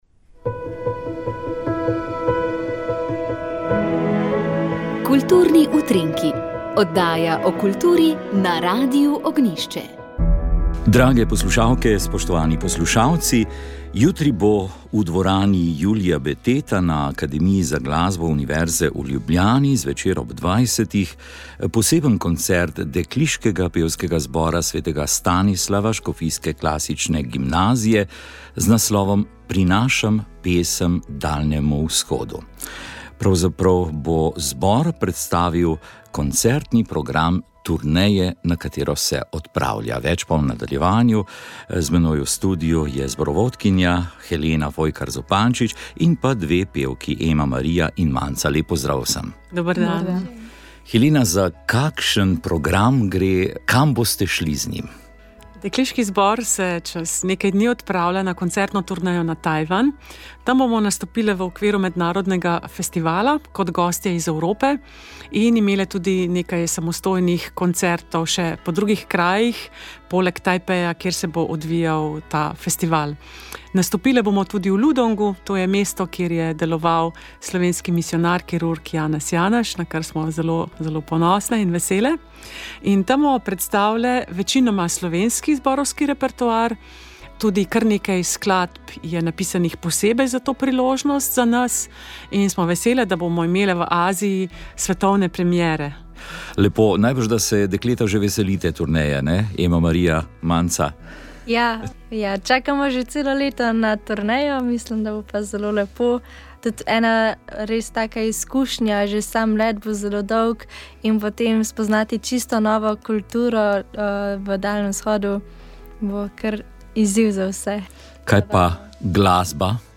Dogajanje nam je v pogovoru